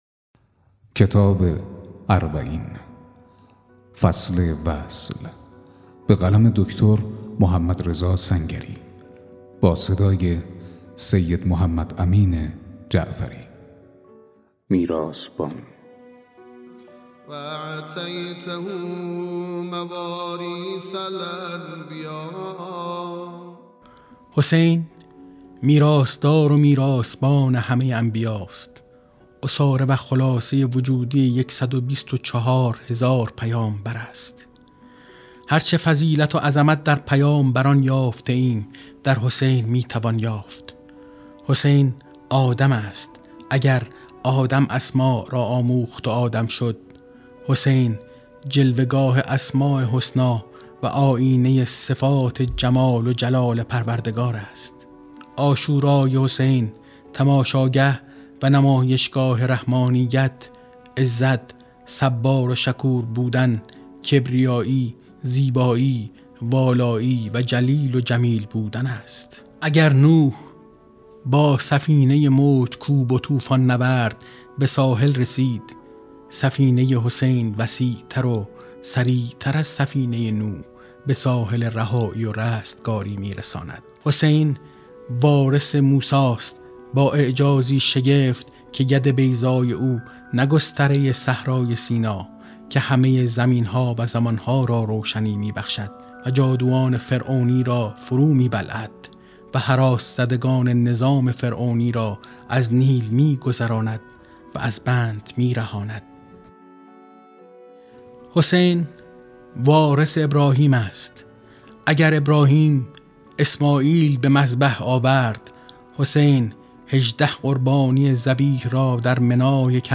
🔻ضبط و آماده‌سازی: استودیو همراز